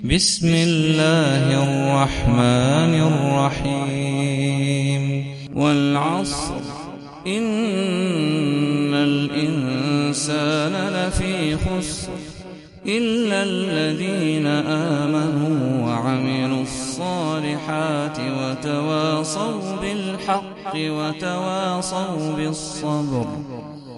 سورة العصر - صلاة التراويح 1446 هـ (برواية حفص عن عاصم)